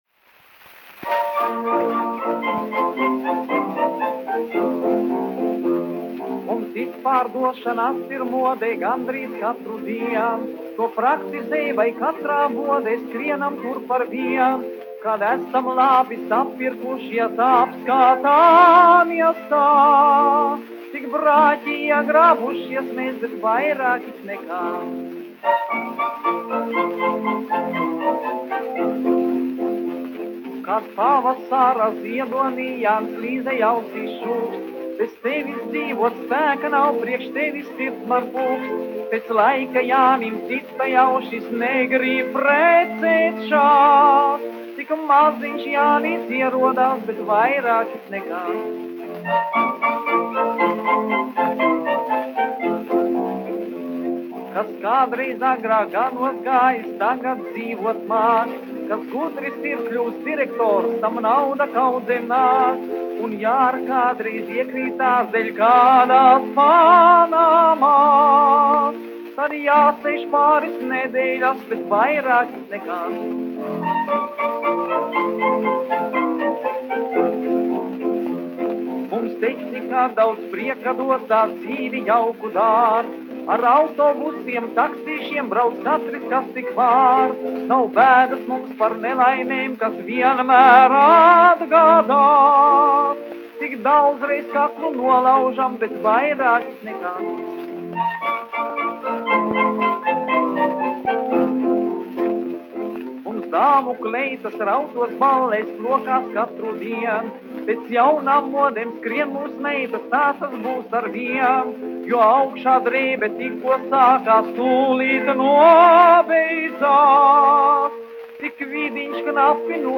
1 skpl. : analogs, 78 apgr/min, mono ; 25 cm
Humoristiskās dziesmas
Skaņuplate